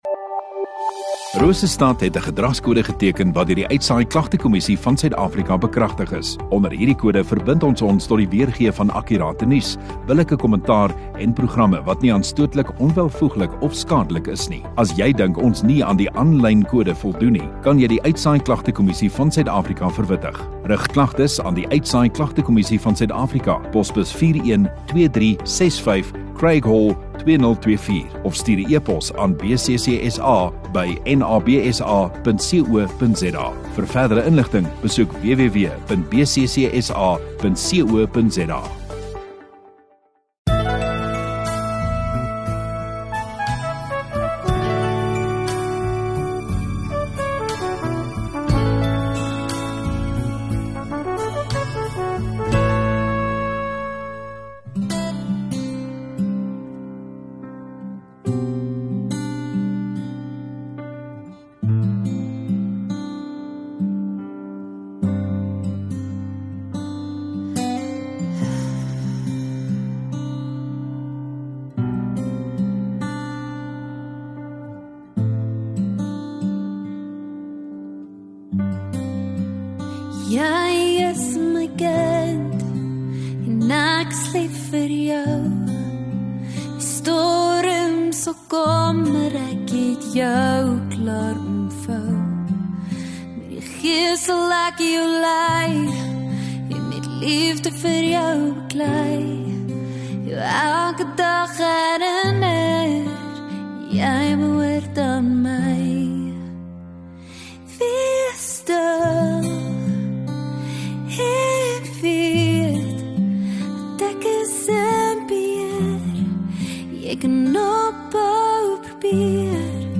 29 Dec Sondagaand Erediens